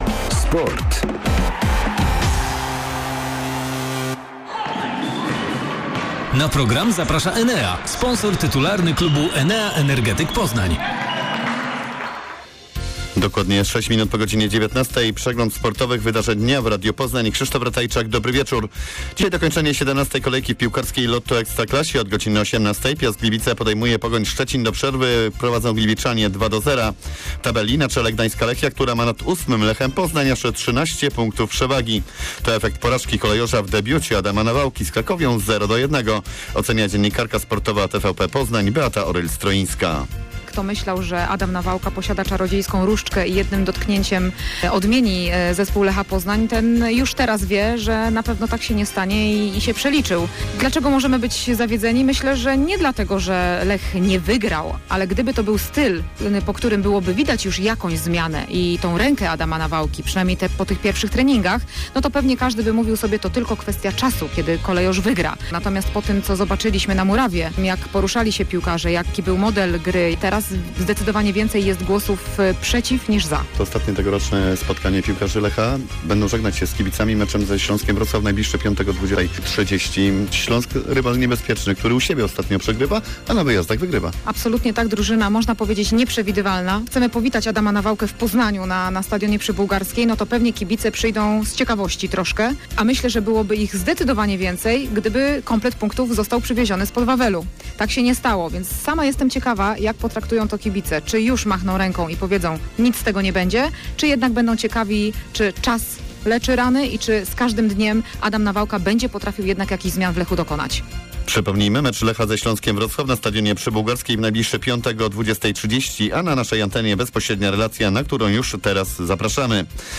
03.12. serwis sportowy godz. 19:05